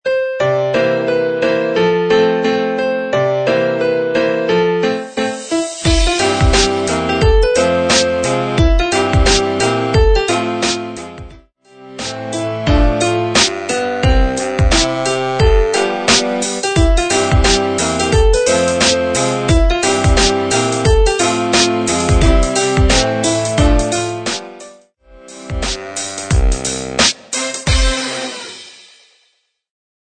88 BPM
Christmas
Urban